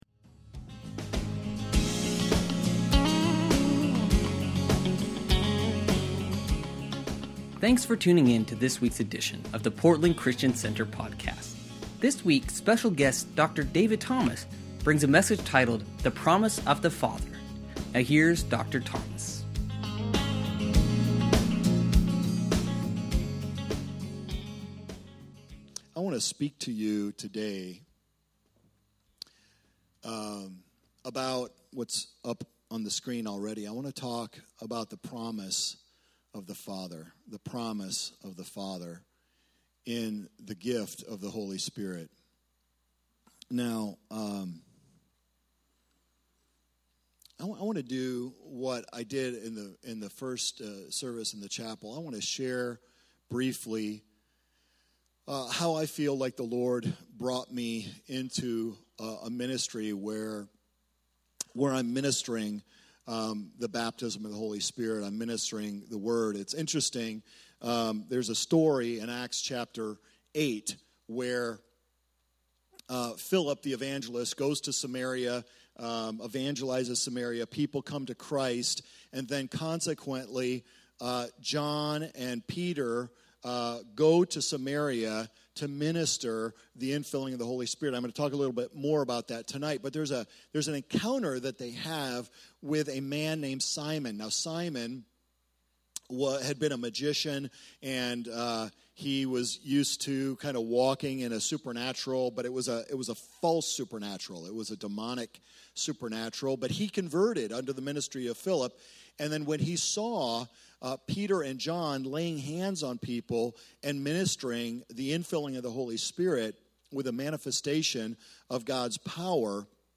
Sunday Messages from Portland Christian Center Songology Jan 21 2018 | 00:56:24 1x 00:00 / 00:56:24 Subscribe Share Spotify RSS Feed Share Link Embed